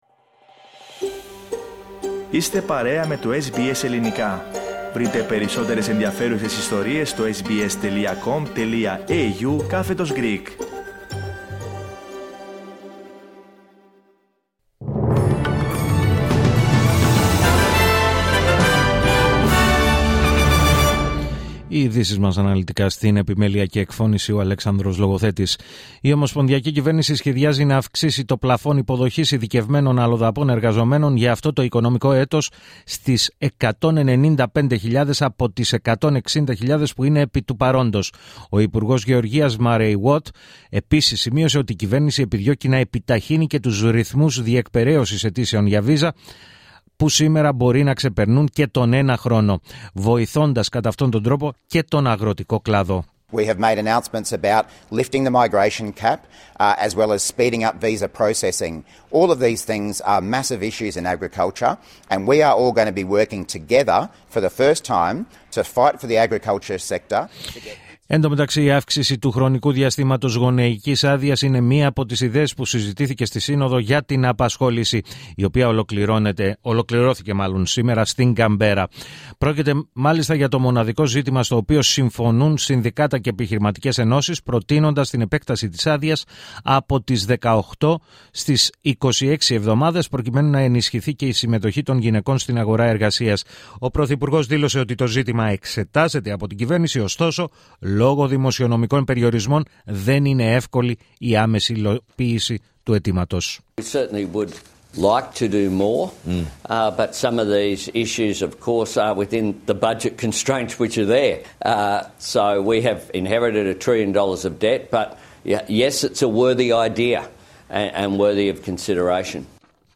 Το αναλυτικό δελτίο ειδήσεων του Ελληνικού Προγράμματος της ραδιοφωνίας SBS, στις 4 μμ.